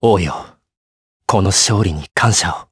Zafir-Vox_Victory_jp.wav